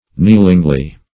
kneelingly - definition of kneelingly - synonyms, pronunciation, spelling from Free Dictionary Search Result for " kneelingly" : The Collaborative International Dictionary of English v.0.48: Kneelingly \Kneel"ing*ly\, adv.